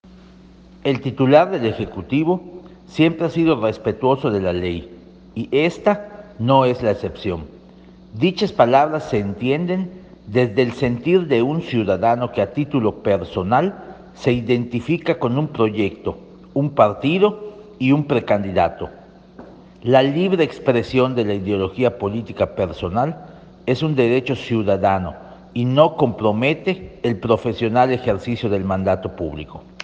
El vídeo fue grabado a la distancia durante el desayuno de los cuadros priistas con el precandidato a la Presidencia, José Antonio Meade Kuribreña, el 19 de diciembre pasado en la Hacienda Chenkú; fue la primera actividad oficial del exsecretario de Hacienda, Desarrollo Social, Relaciones Exteriores y Energía (esta cartera, en el sexenio de Felipe Calderón Hinojosa).